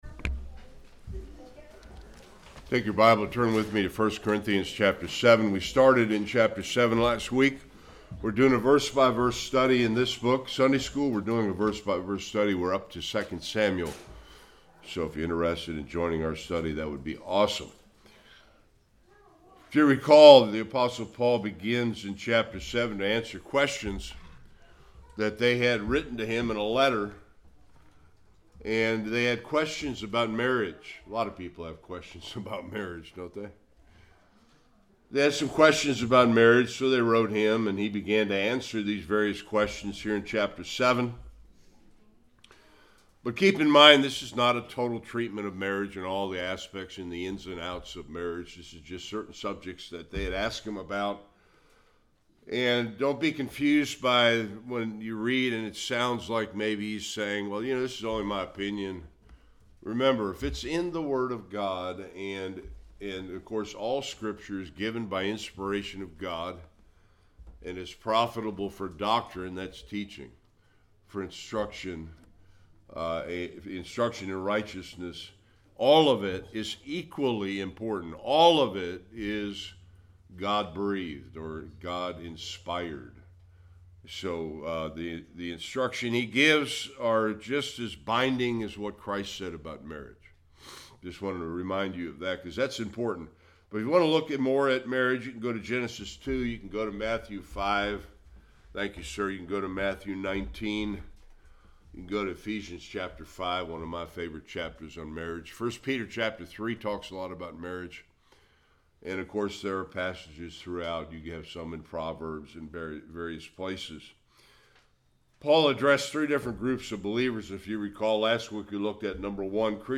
25-40 Service Type: Sunday Worship Paul answered the question